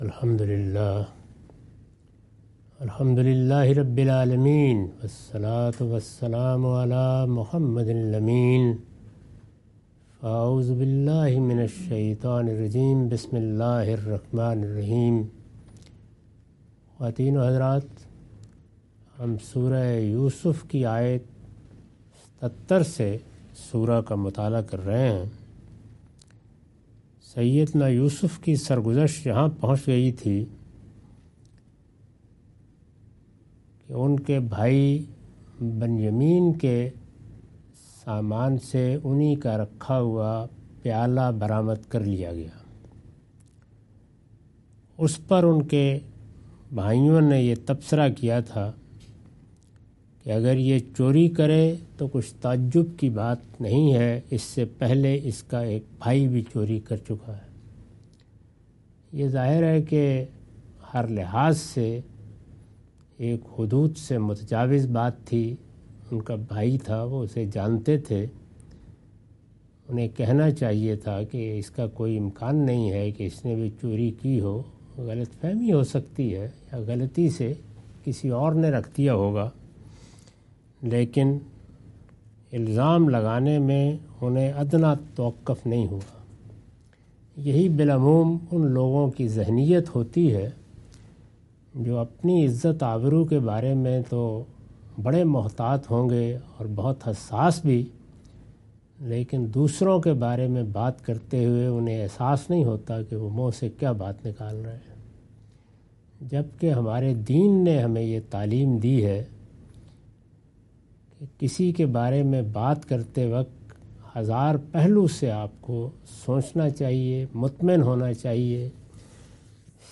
Surah Yousuf - A lecture of Tafseer-ul-Quran – Al-Bayan by Javed Ahmad Ghamidi. Commentary and explanation of verses 77-83.